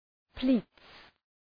Προφορά
{pli:ts}
pleats.mp3